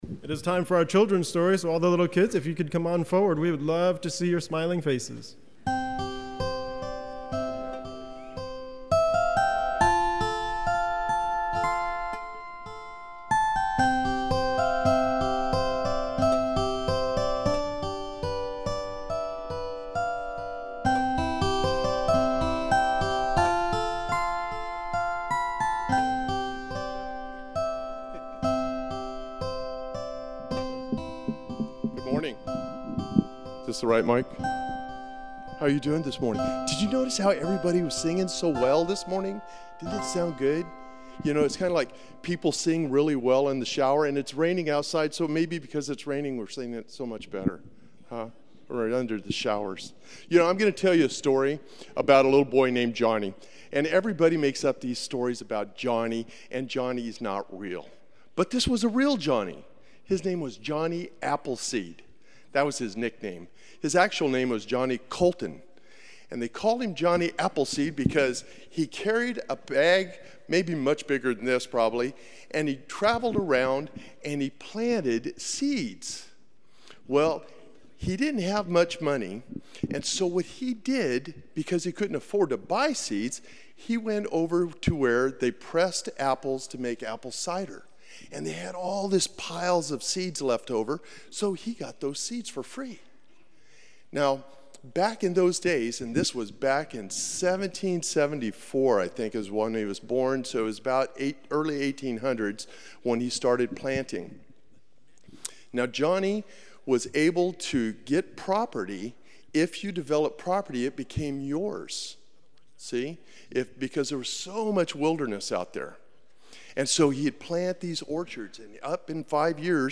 Children Story